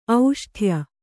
♪ auṣṭhya